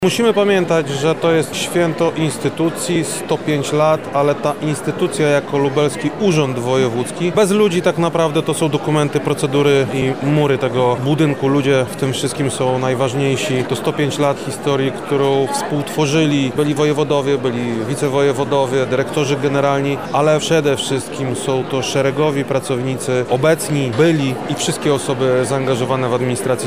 Swoje przemyślenia o wkładzie pracowników w rozwój instytucji mówi Krzysztof Komorski, wojewoda lubelski: